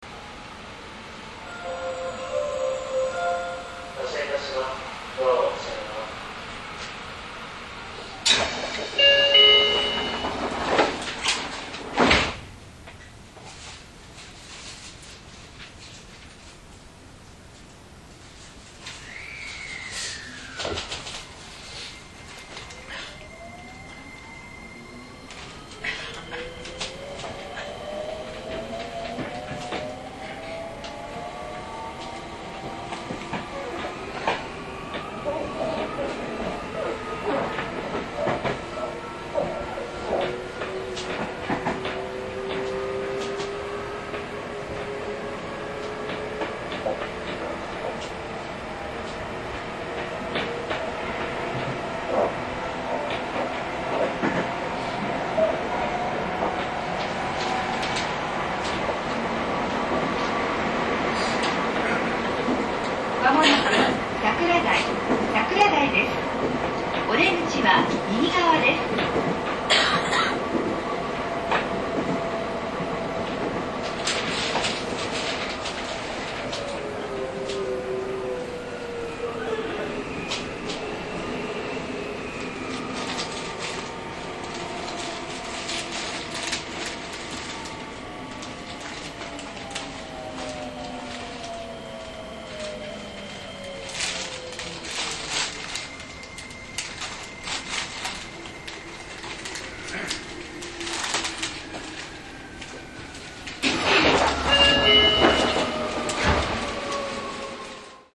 インバーター制御ですが、モーター音が静が過ぎです。
走行音